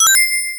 HitsoundRing.ogg